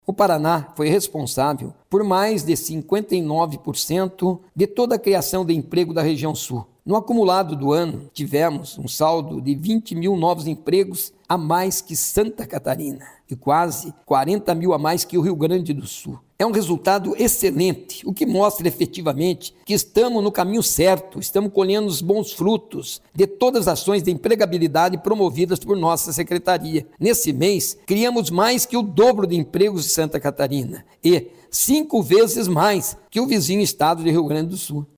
Sonora do secretário do Trabalho, Qualificação e Renda, Mauro Moraes, sobre os índices de empregabilidade do Paraná em 2023